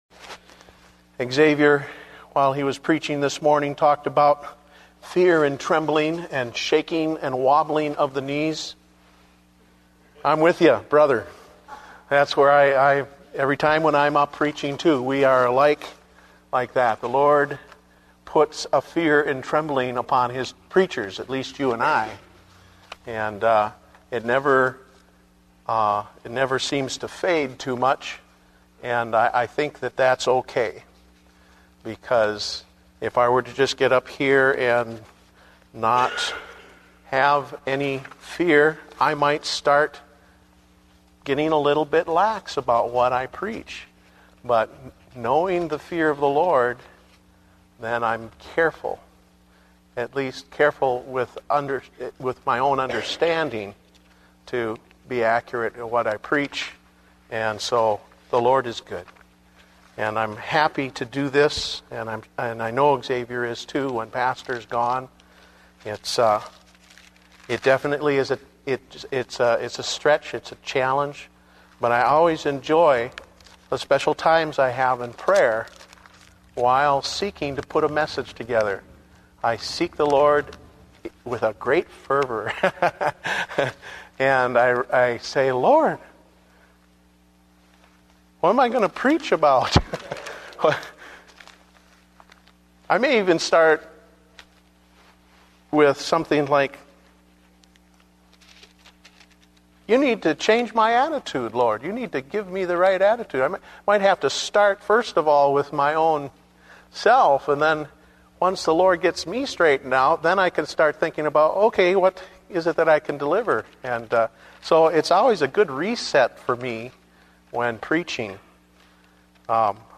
Date: October 25, 2009 (Evening Service)